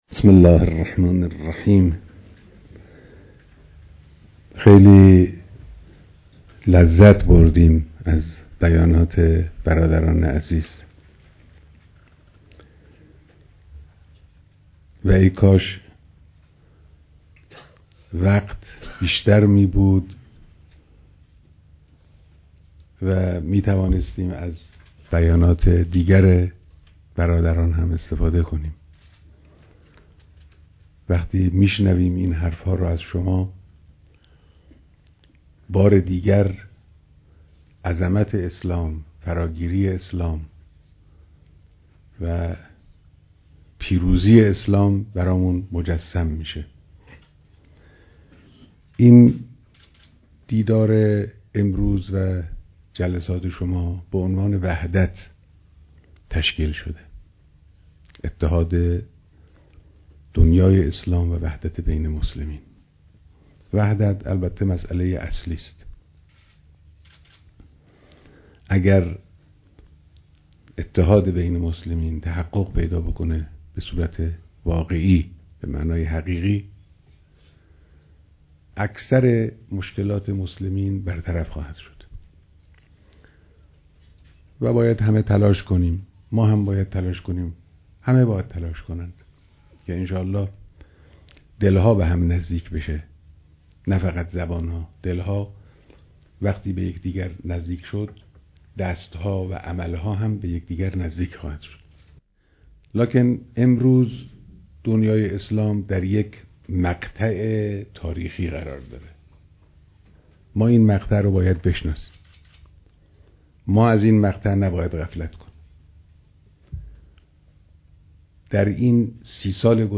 بيانات در ديدار شركت‌كنندگان دركنفرانس وحدت اسلامی